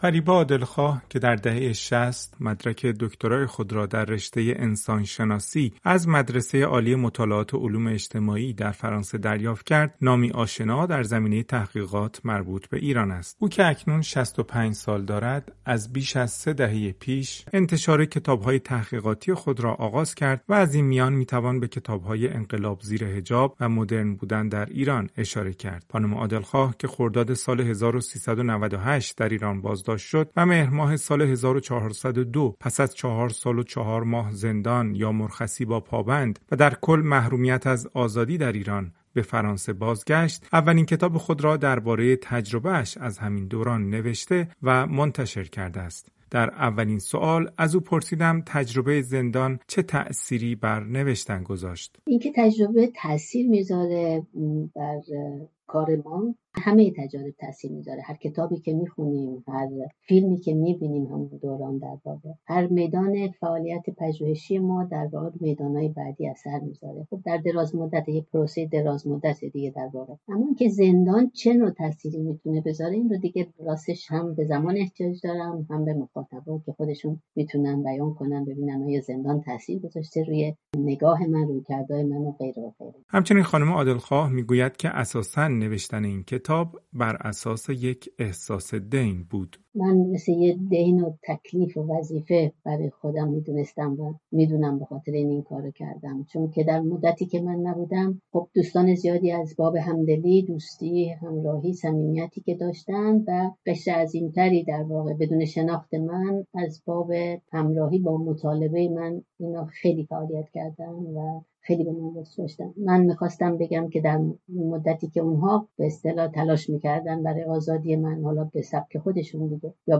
صدایم را نتوانستند حبس کنند؛ گفت‌وگو با فریبا عادلخواه درباره کتاب جدیدش